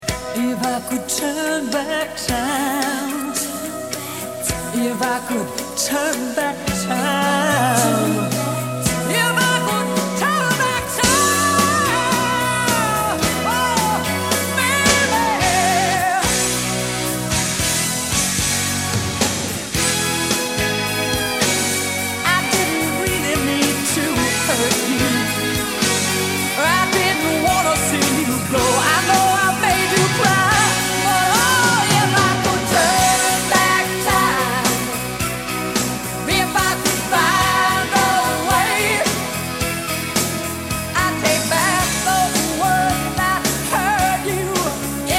My Favorite Songs with Notable Bass Guitar Performances